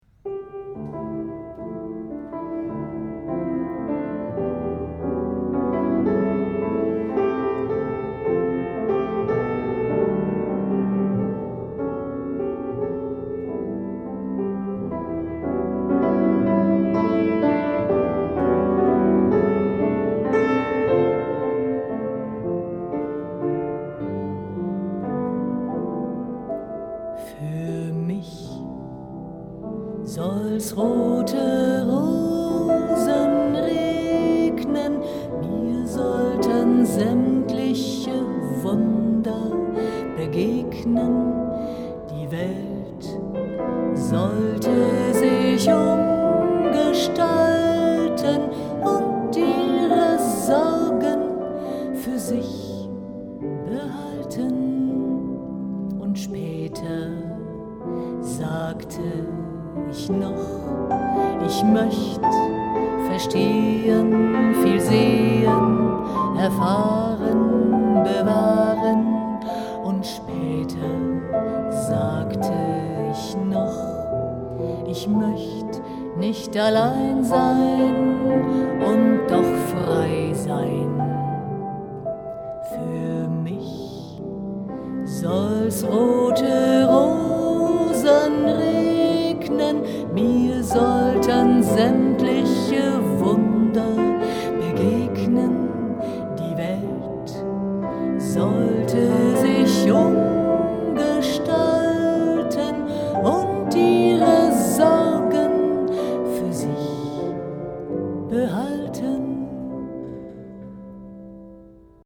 Gesang – Jazz, Schlager, Tanzmusik